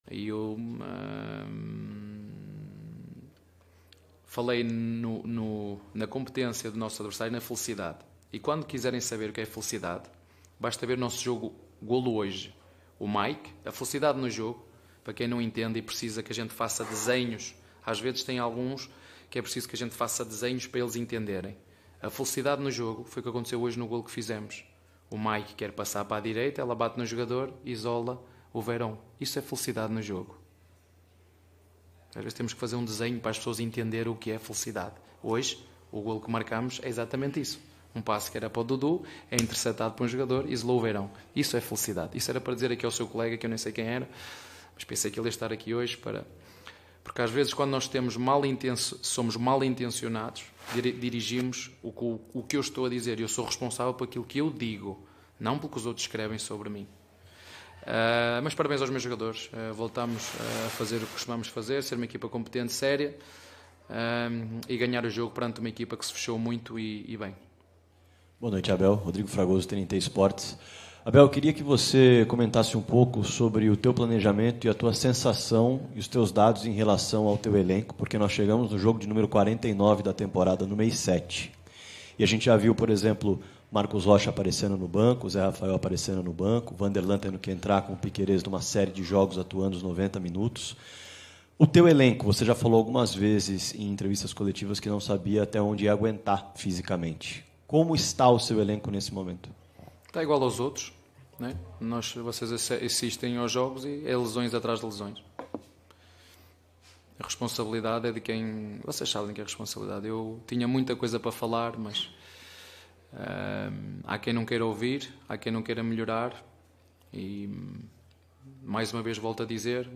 COLETIVA-ABEL-FERREIRA-_-PALMEIRAS-X-CUIABA-_-BRASILEIRO-2022.mp3